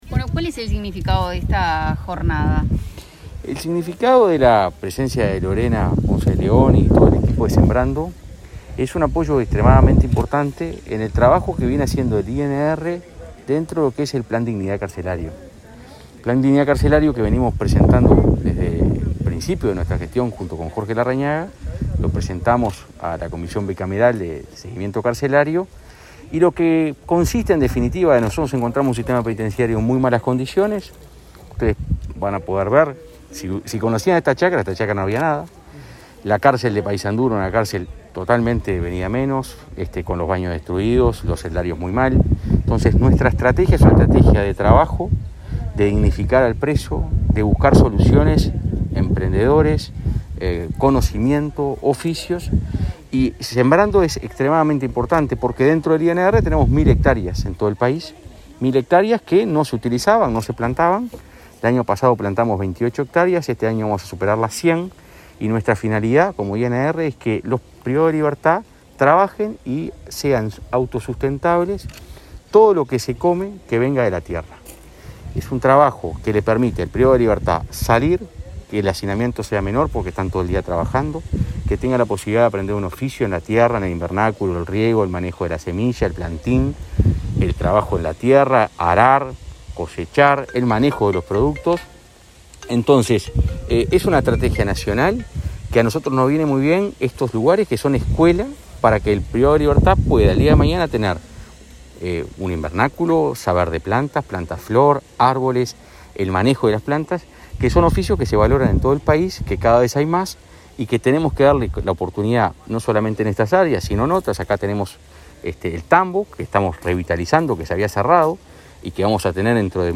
Declaraciones a la prensa del director de Convivencia del Ministerio del Interior, Santiago González
Declaraciones a la prensa del director de Convivencia del Ministerio del Interior, Santiago González 19/08/2021 Compartir Facebook X Copiar enlace WhatsApp LinkedIn En el marco de la inauguración de una huerta del programa Sembrando, en la unidad carcelaria n.° 6 de Paysandú, el director de Convivencia del Ministerio del Interior, Santiago González, dialogó con la prensa.